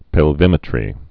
(pĕl-vĭmĭ-trē)